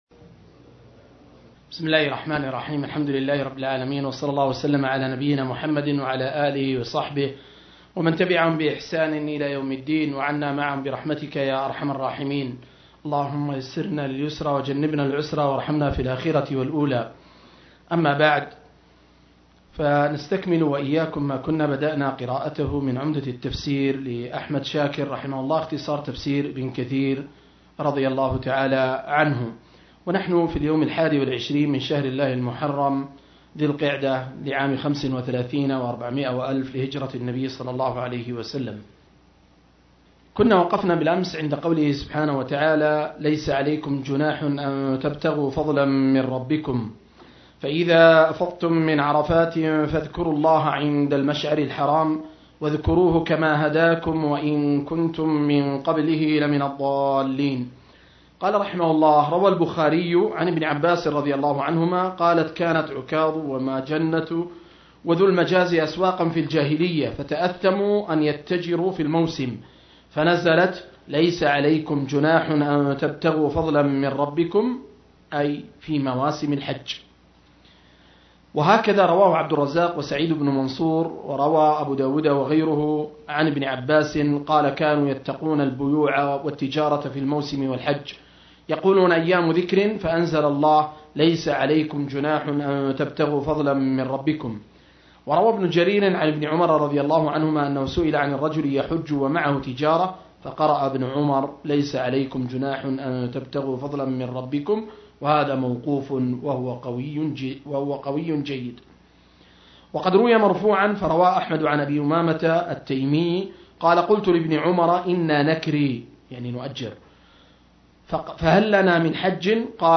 041- عمدة التفسير عن الحافظ ابن كثير – قراءة وتعليق – تفسير سورة البقرة (الآيات 203-198)